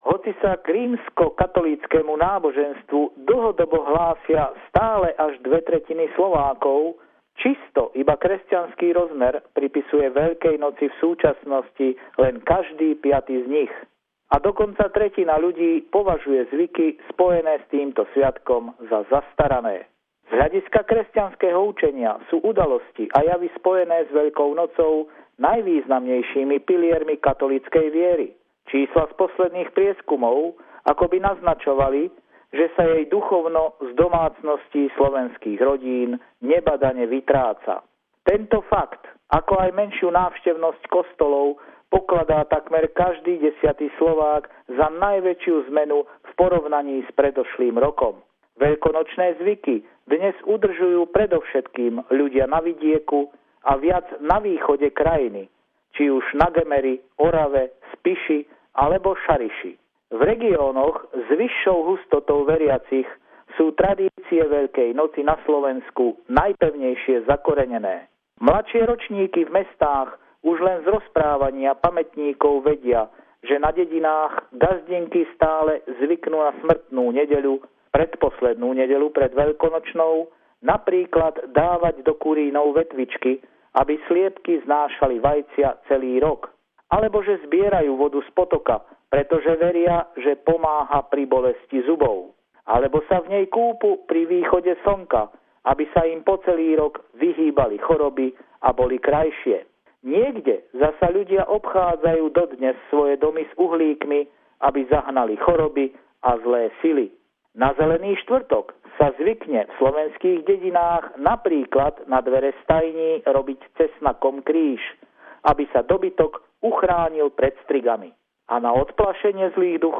Stringer report from Bratislava about the Slovak Easter traditions